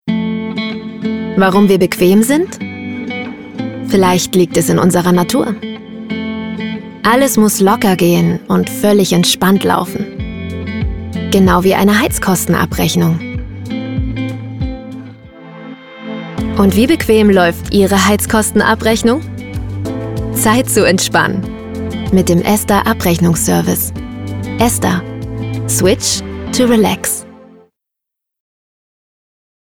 sehr variabel
Jung (18-30)
Sächsisch
Commercial (Werbung)